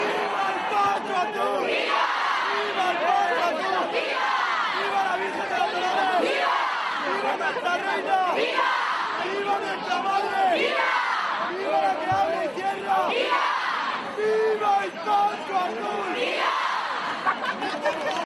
La "locura azul" se desató a medianoche a las puertas de la iglesia de San Francisco en la Serenata del Paso Azul a la Virgen de los Dolores, con la que se ha iniciado la Semana Santa en Lorca.
La recibieron con vivas y piropos mientras agitaban pañuelos azules.